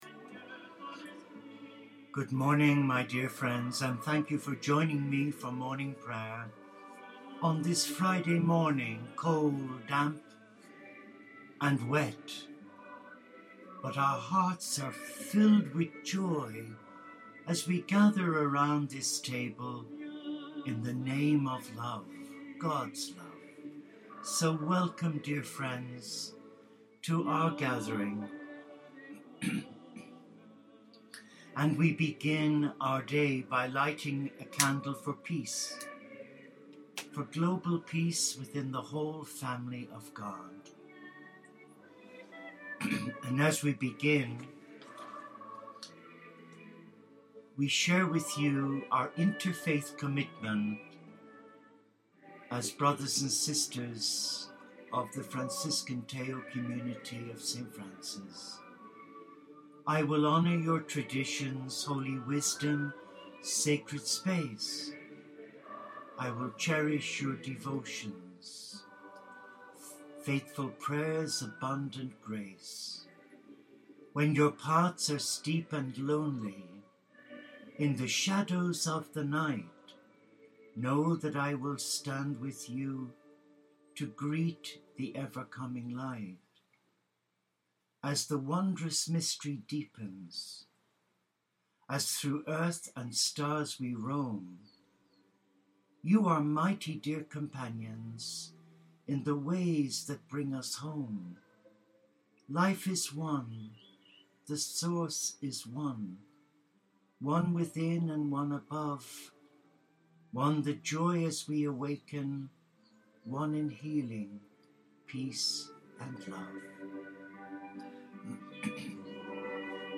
This Podcast is a live recording our our Morning Prayer of Lauds with an eclectic inter-spiritual flavor to acknowledge that as Interfaith Celtic Franciscans we are all children of the same God/Dess albeit known by many different names. We are all equal, loved and forgiven but we are responsible for one another as we are each others brother and sister, members of the Mystical heart of A Loving Father Mother God.